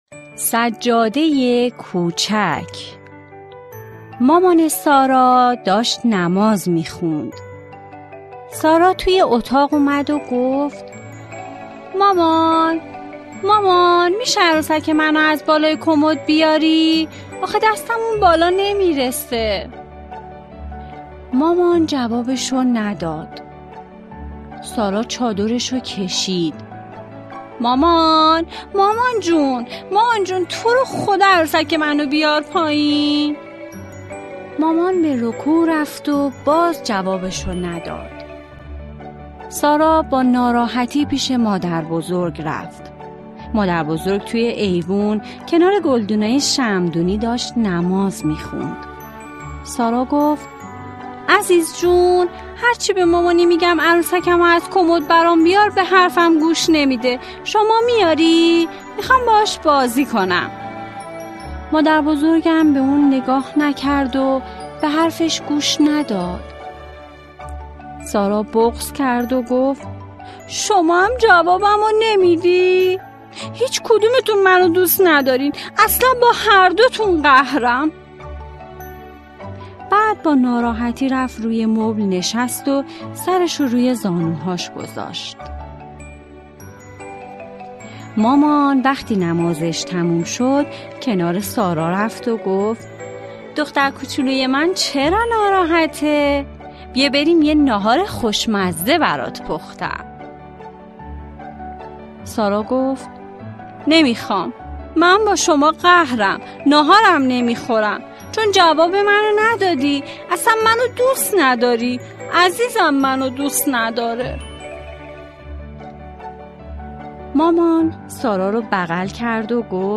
قصه کودکانه